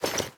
equip_diamond2.ogg